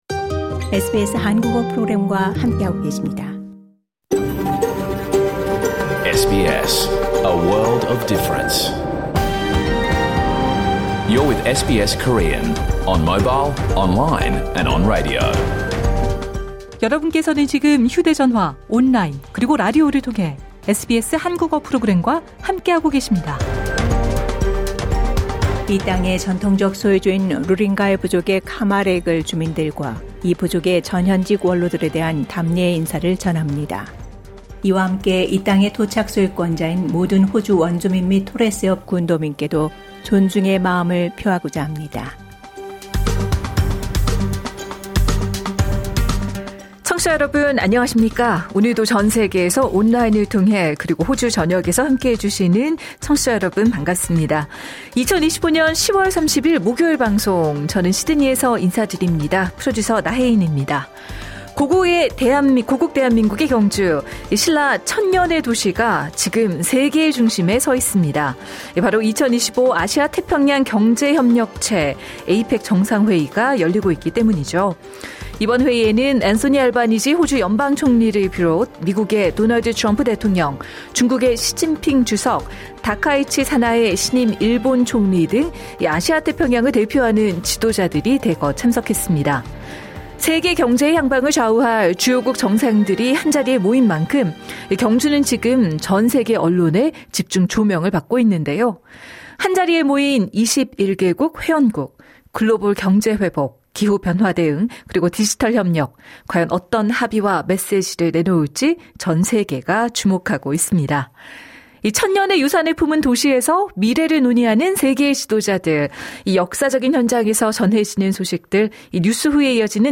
2025년 10월 30일 목요일에 방송된 SBS 한국어 프로그램 전체를 들으실 수 있습니다.